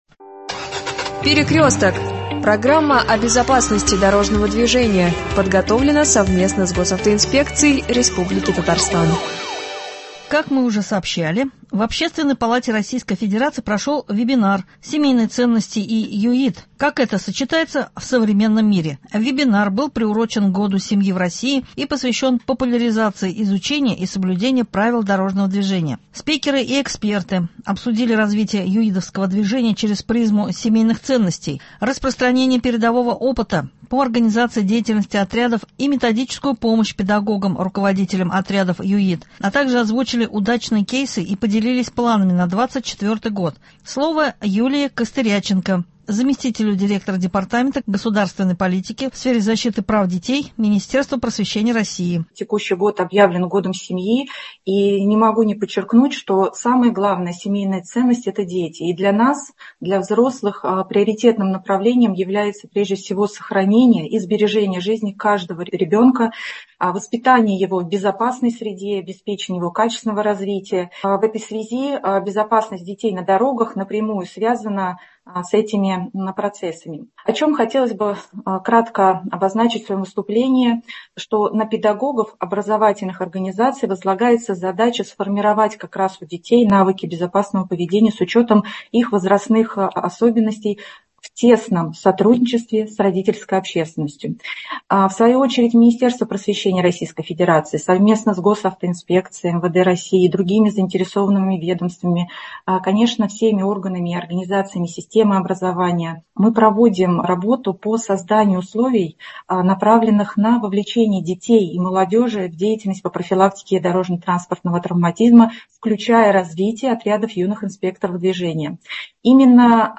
С наступление тепла на дороги выехали мотоциклы. Об их безопасности на дорогах говорит Эльнар Садриев — начальник Отдела ГИБДД УМВД России по Казани.